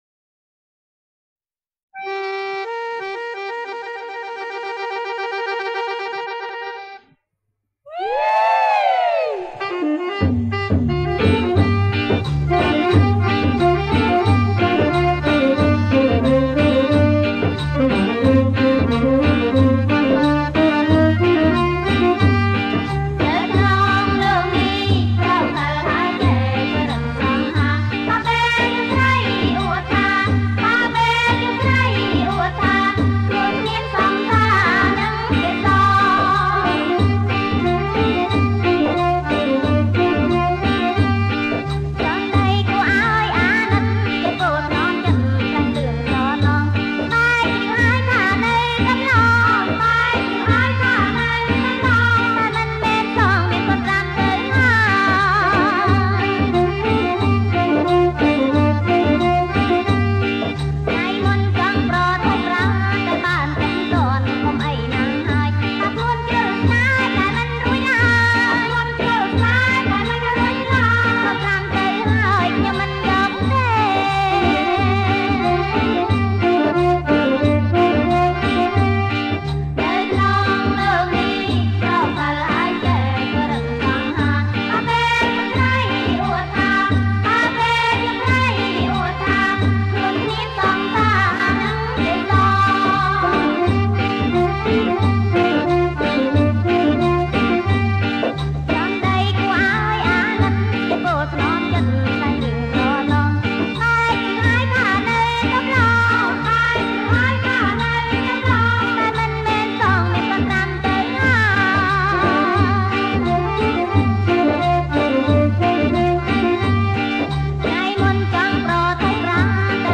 ប្រគំជាចង្វាក់ រាំវង់